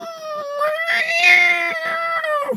cat_2_meow_long_05.wav